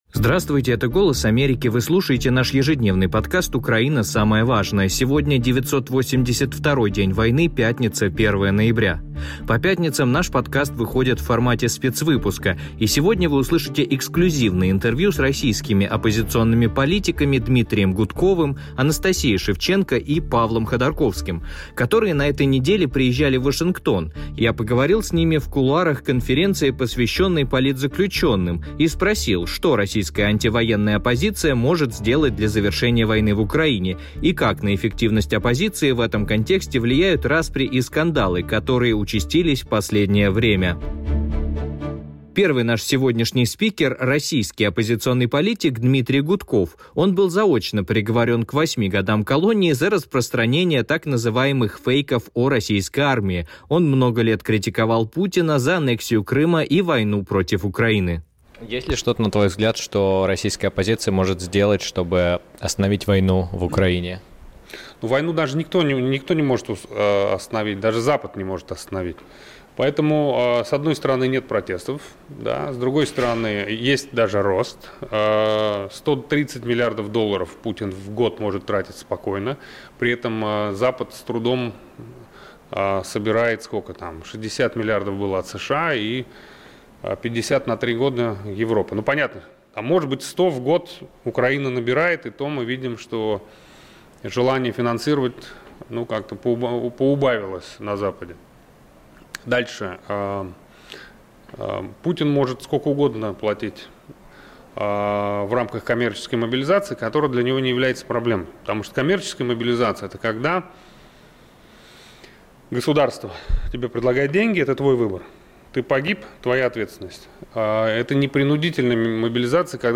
эксклюзивные интервью